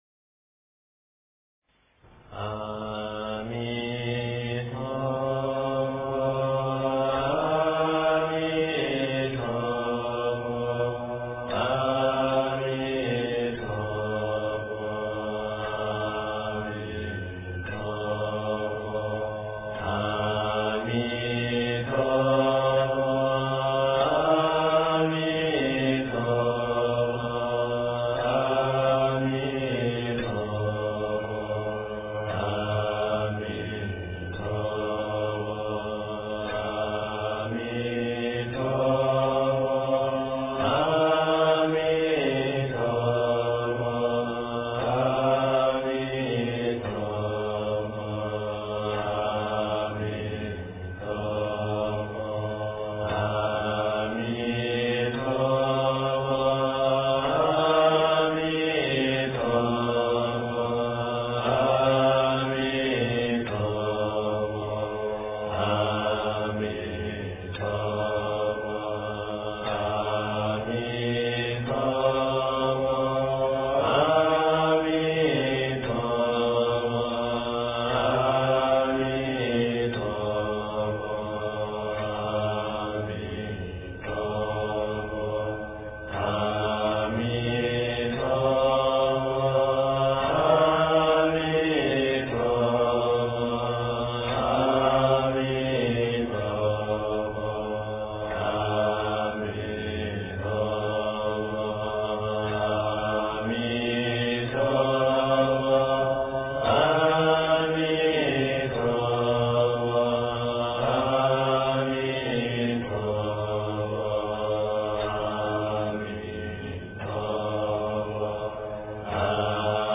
阿弥陀佛--僧团佛七 经忏 阿弥陀佛--僧团佛七 点我： 标签: 佛音 经忏 佛教音乐 返回列表 上一篇： 南无佛陀--佚名 下一篇： 南无阿弥陀佛--男女6音调 相关文章 药师经-赞--圆光佛学院众法师 药师经-赞--圆光佛学院众法师...